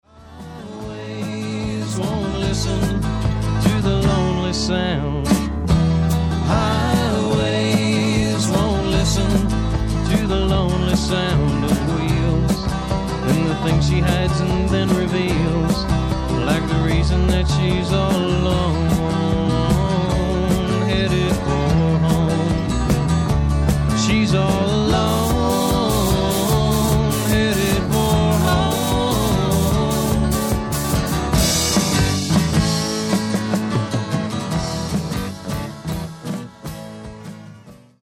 AMERICAN ROCK